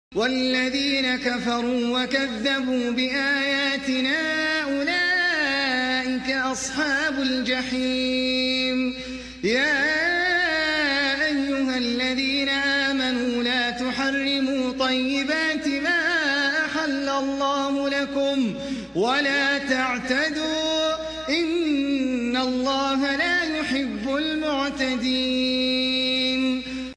quran recitation beautiful voice